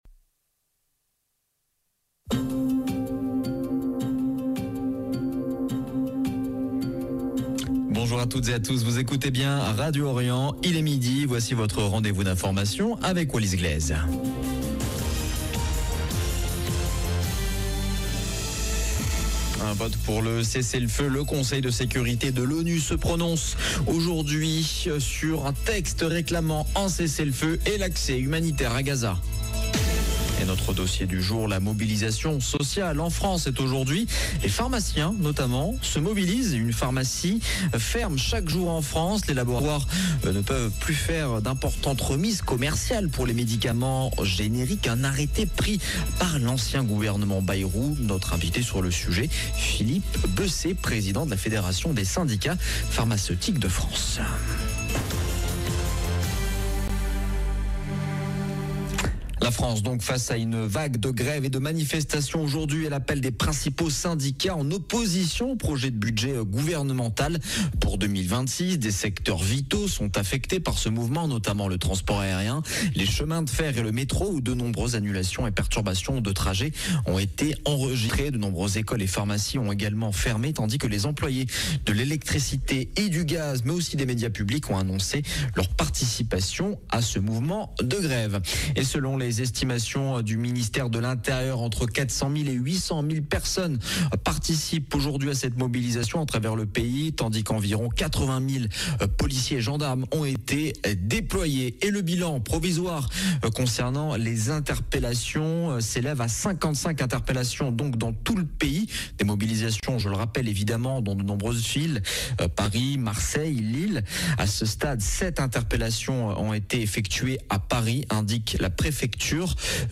Journal de midi du jeudi 18 septembre 2025